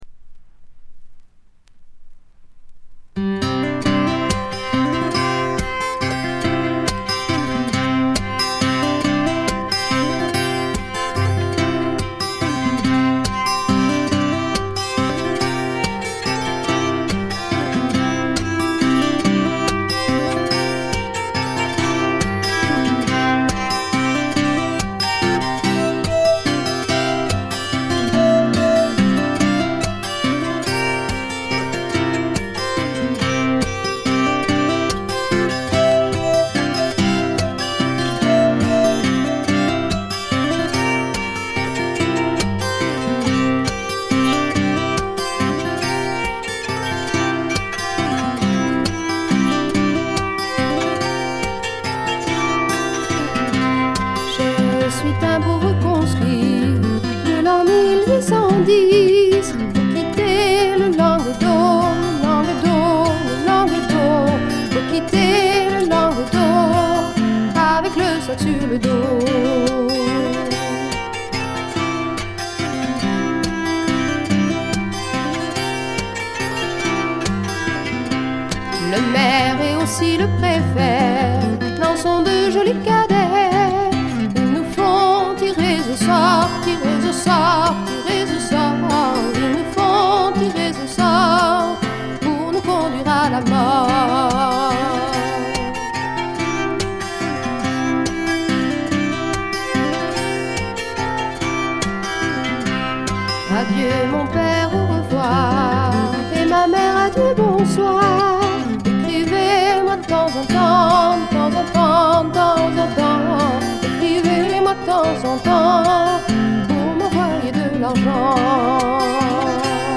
ごくわずかなノイズ感のみ。
両曲とも長尺で後半がヴォーカルパートになっています。
試聴曲は現品からの取り込み音源です。
Recorded at studio Kamboui, Chatellerault, France.